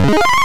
The sound Pooka makes in Namco Roulette
Nr_pooka_sound.ogg